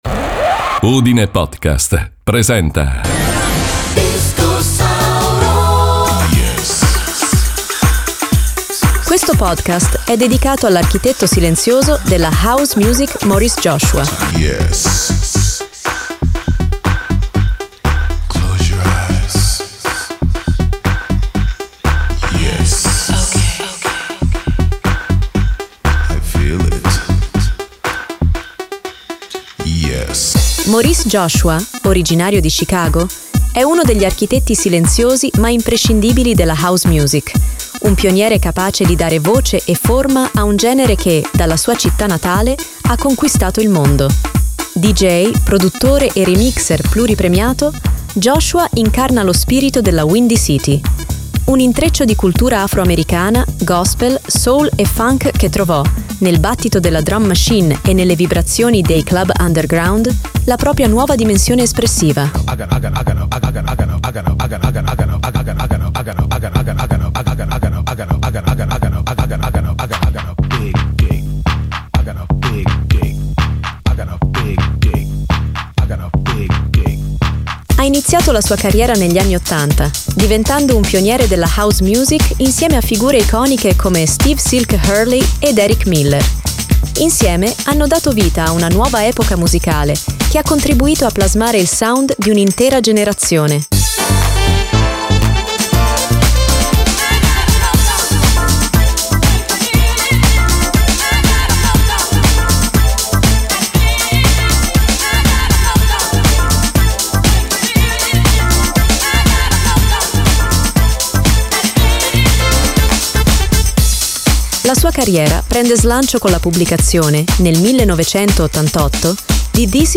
La sua musica è caratterizzata da bassi profondi, voci soulful e una percussione intricata che crea un’energia contagiosa, capace di travolgere club e festival in tutto il mondo.